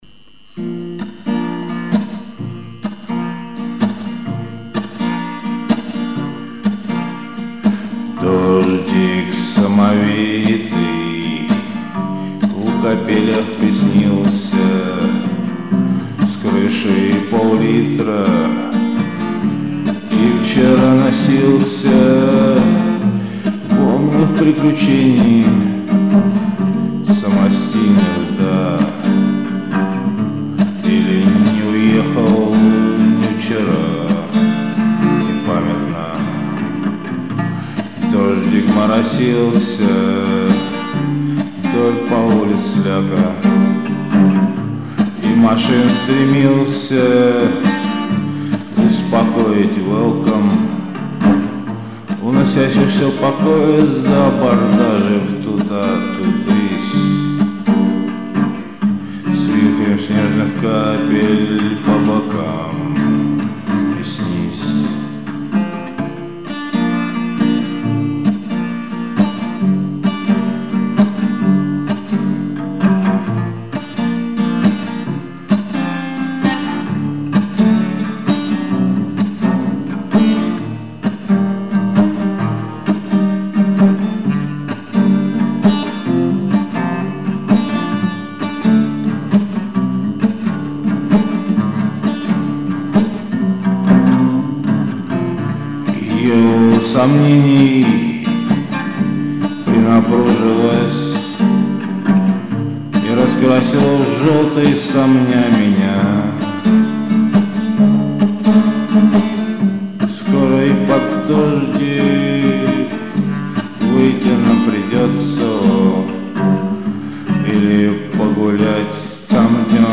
Достаточно заунывно, все как я люблю...
Стихи читать невозможно, а гитара и непонятный вокал даже и ничего.